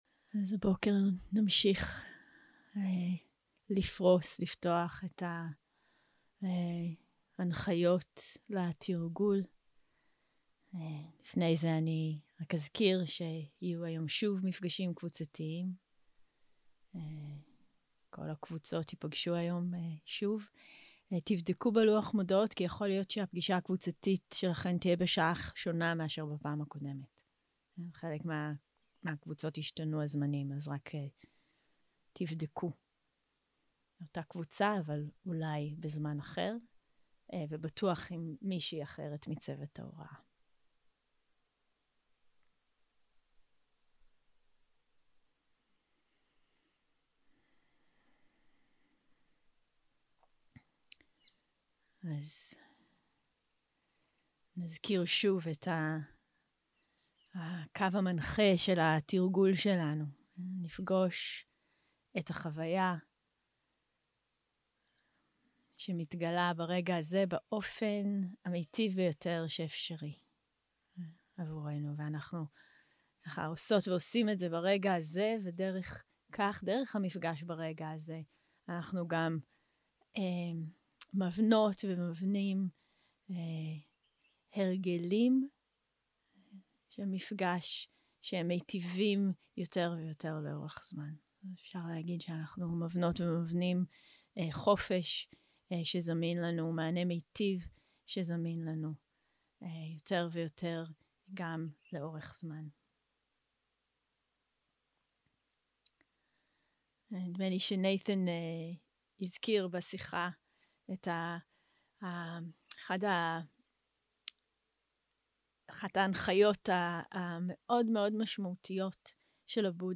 יום 5 - הקלטה 11 - בוקר - הנחיות למדיטציה - סבלנות, התמדה ומשחקיות בתרגול 0:00 0:00 סוג ההקלטה: סוג ההקלטה: שיחת הנחיות למדיטציה שפת ההקלטה: שפת ההקלטה: עברית